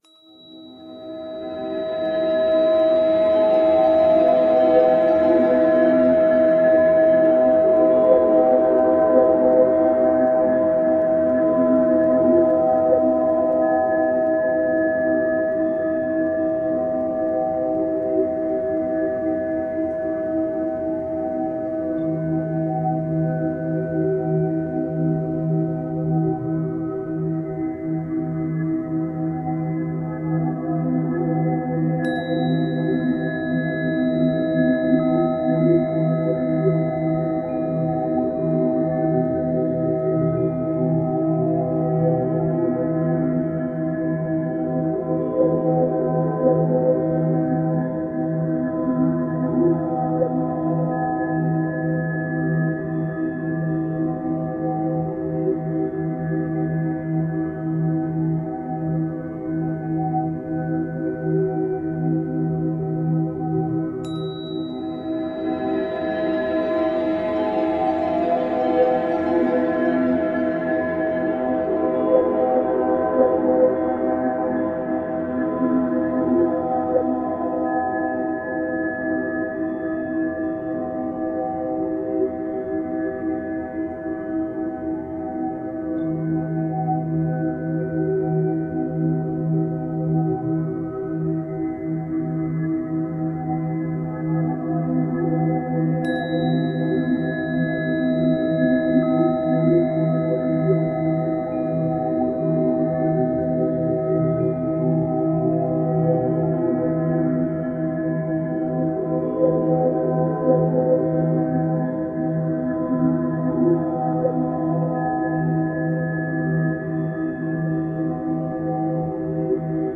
693Hz – فرکانس 693 هرتز
در این فصل می‌خوایم براتون یک سری موسیقی با فرکانس‌های مختلف قرار بدیم که بهشون تون هم می‌گن.